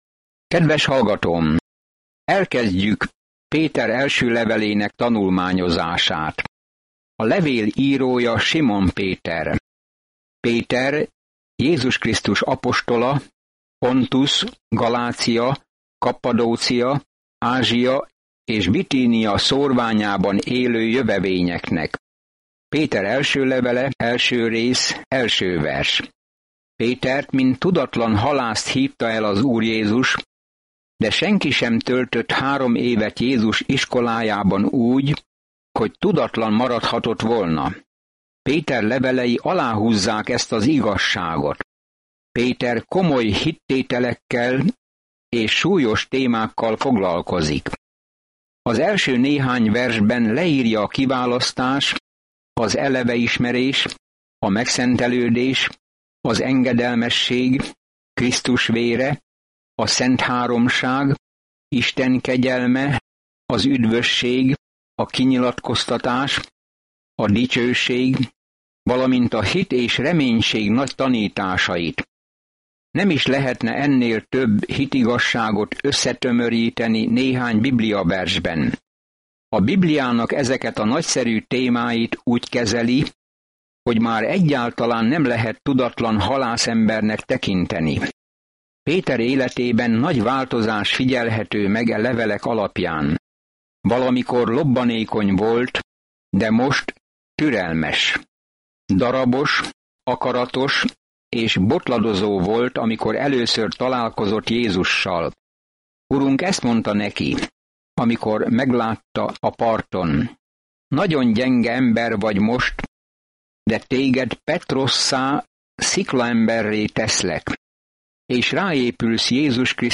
Szentírás 1Péter 1:1-2 Olvasóterv elkezdése Nap 2 A tervről Ha Jézusért szenvedsz, akkor ez az első levél Pétertől arra biztat, hogy Jézus nyomdokaiba lépsz, aki először szenvedett értünk. Napi utazás 1 Péteren keresztül, miközben hallgatod a hangos tanulmányt, és olvasol válogatott verseket Isten szavából.